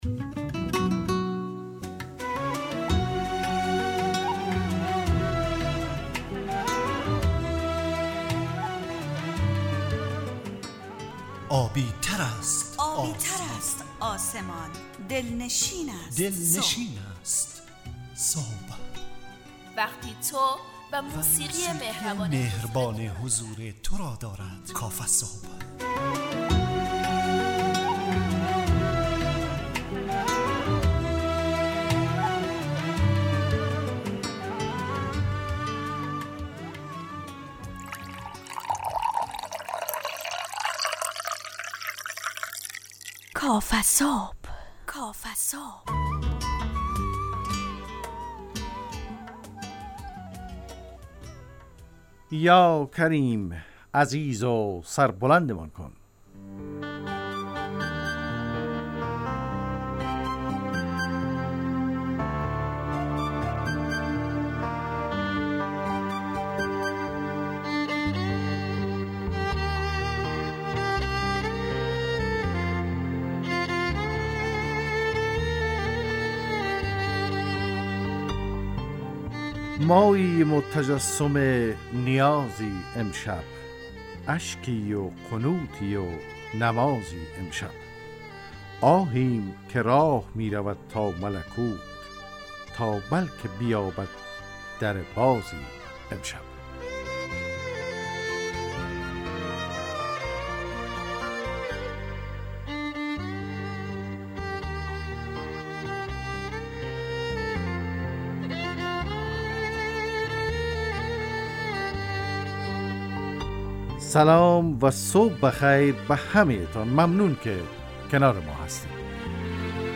کافه‌صبح – مجله‌ی صبحگاهی رادیو دری با هدف ایجاد فضای شاد و پرنشاط صبحگاهی همراه با طرح موضوعات اجتماعی، فرهنگی و اقتصادی جامعه افغانستان با بخش‌های کارشناسی، هواشناسی، نگاهی به سایت‌ها، گزارش، گپ صبح، صبح جامعه و صداها و پیام‌ها شنونده‌های عزیز